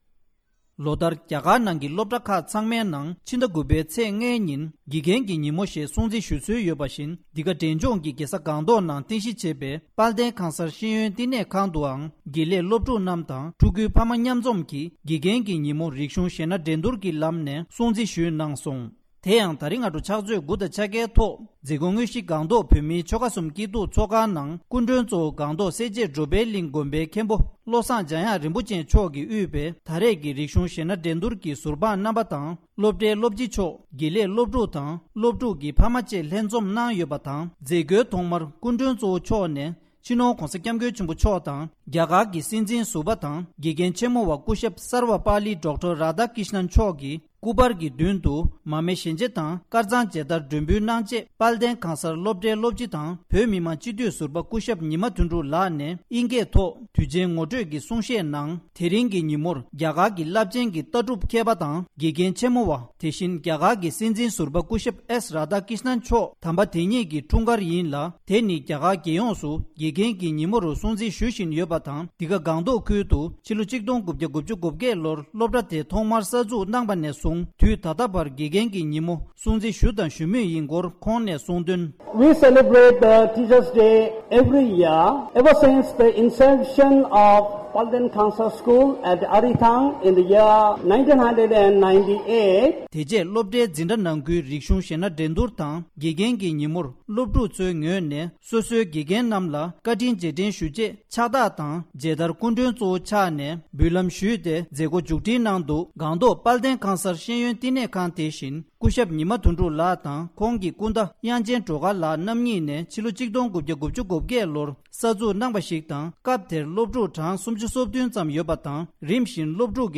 སྒྲ་ལྡན་གསར་འགྱུར།